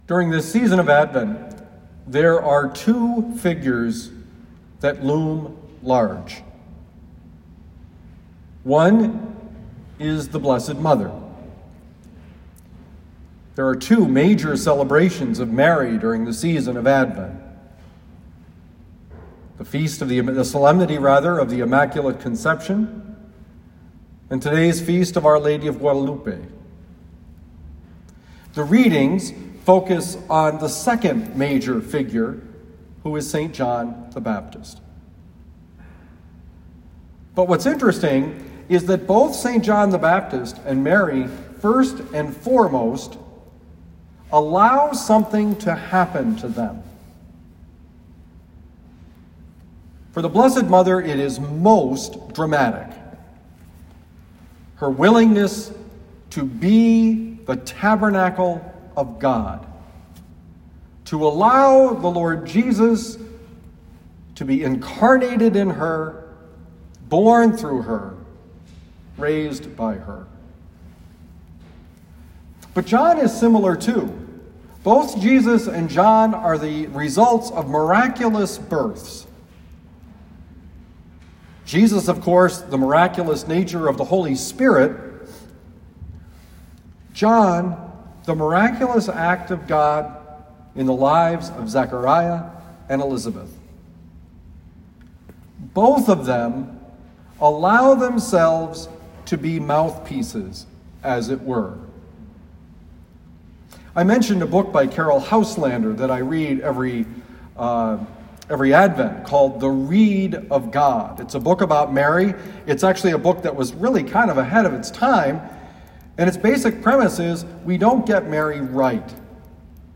Homily for December 13, 2020
Given at Our Lady of Lourdes Parish, University City, Missouri